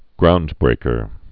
(groundbrākər)